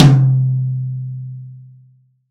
Index of /90_sSampleCDs/AKAI S6000 CD-ROM - Volume 3/Drum_Kit/DRY_KIT1
M-TOM12C-1-S.WAV